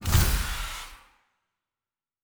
Sci-Fi Sounds / Doors and Portals / Door 5 Close.wav
Door 5 Close.wav